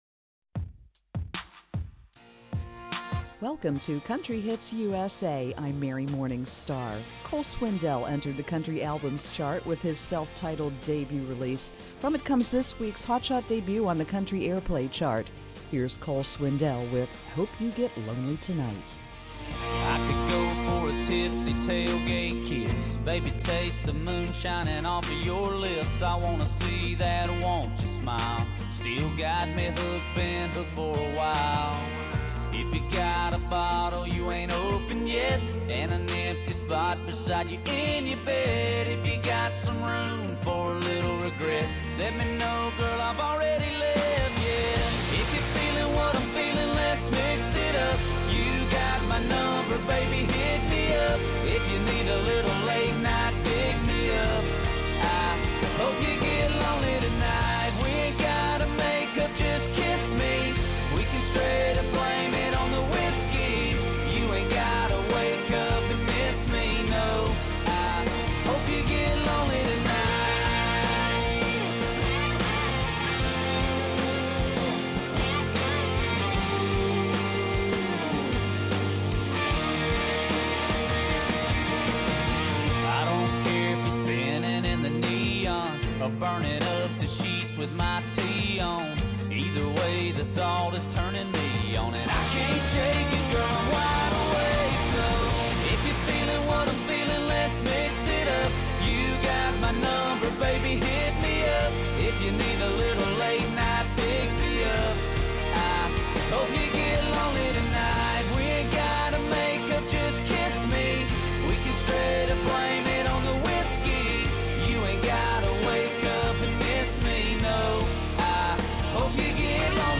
Country music hits